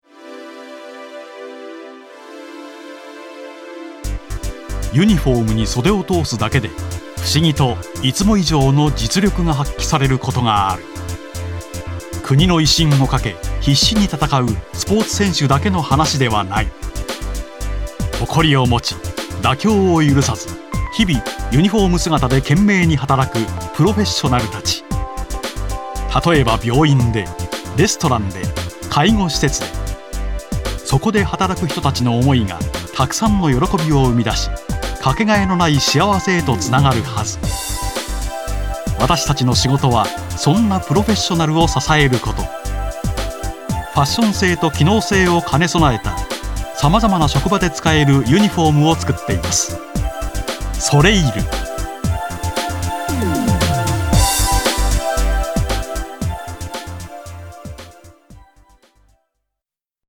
一方シリアスなストーリーテーラー的語り、信頼感と暖かみのある落ち着いたナレーション、味のある芝居など様々な持ち味は、どんな作品でも力を発揮。